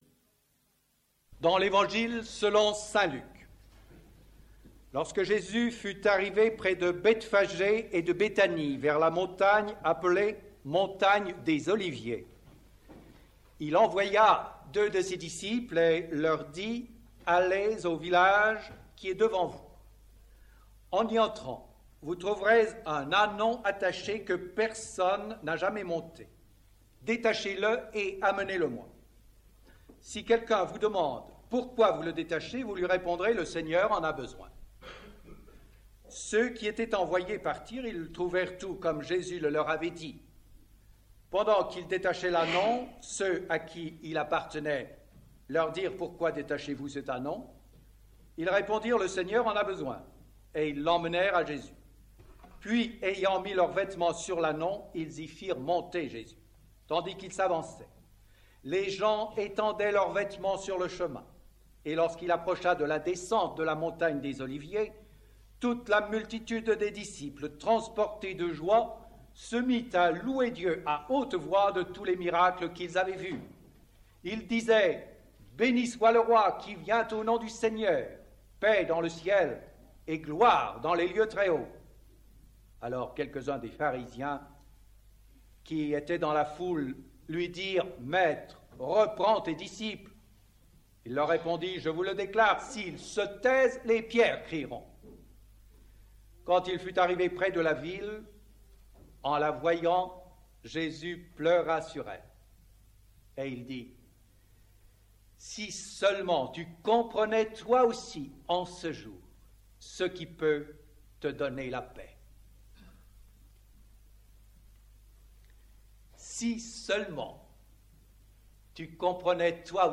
culte du 19 mars 1978 à Temple de Yens